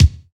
Index of /90_sSampleCDs/Roland - Rhythm Section/KIT_Drum Kits 4/KIT_Ping Kit